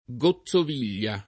goZZov&l’l’a o